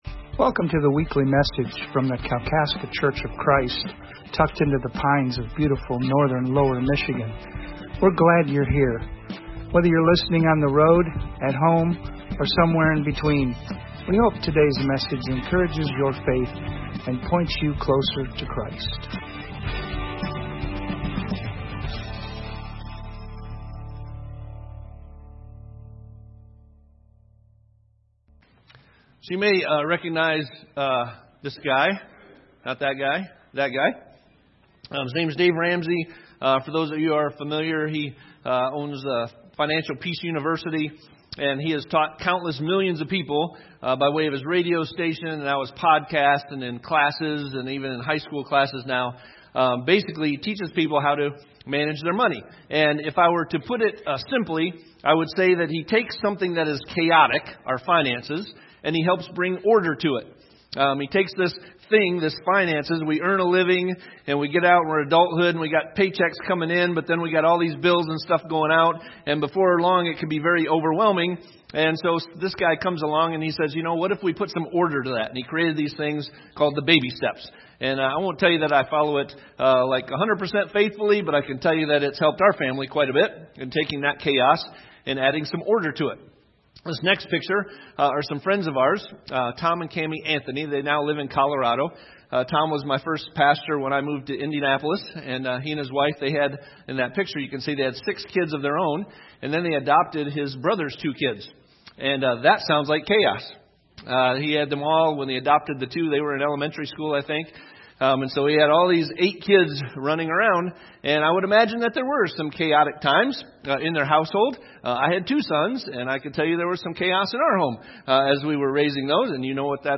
Jesus is tempted by Satan 3 times and shows us how to resist. This sermon is from Matthew chapter 4.